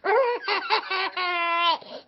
peekaboo3.ogg